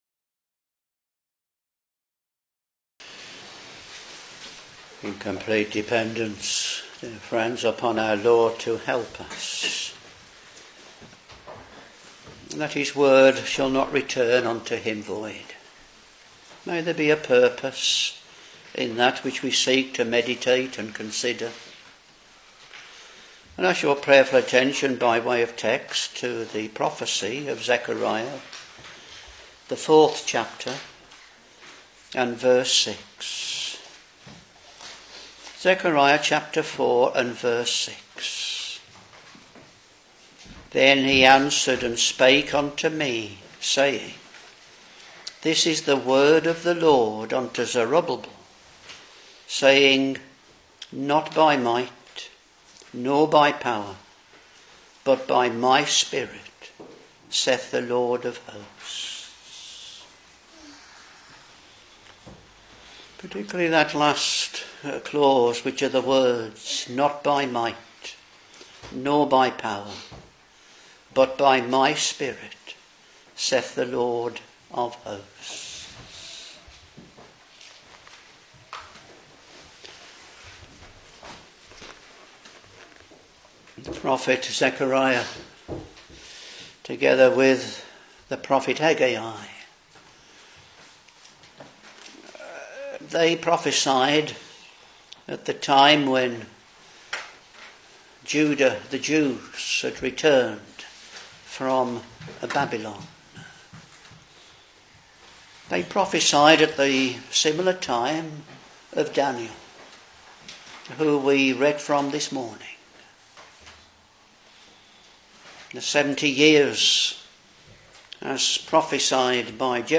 Back to Sermons